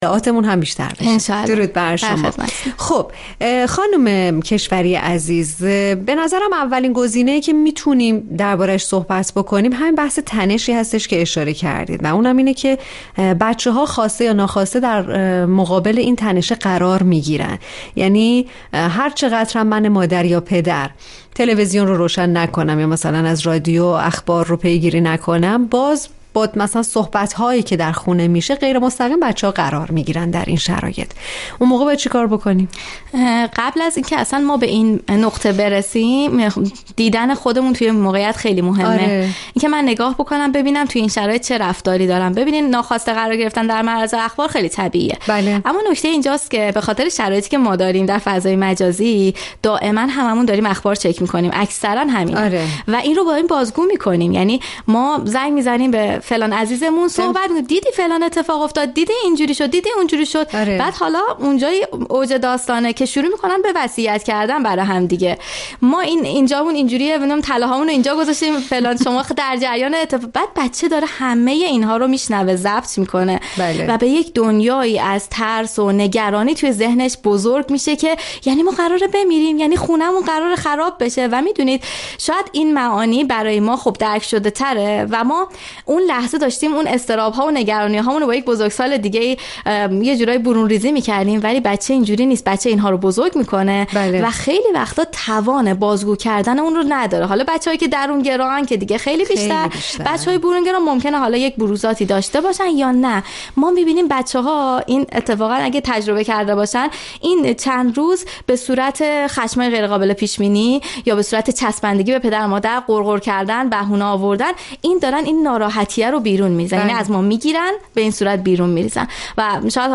برنامه رادیویی «زندگی زیباست» به موضوع كنترل رفتار فرزندان كودك و نوجوان ناشی از تنش های حوادث اخیر پرداخت